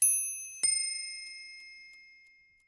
CLOCK_DING-DONG_bright.aiff
chime chiming clock dong hour sound effect free sound royalty free Sound Effects